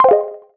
unselect_01.wav